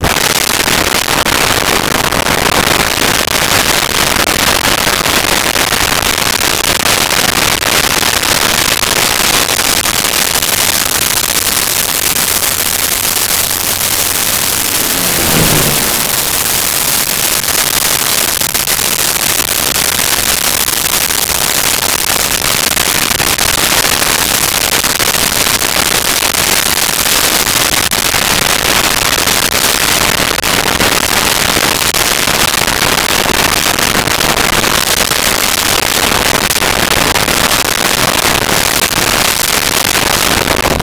Plane Tigermoth Fast By
Plane Tigermoth Fast By.wav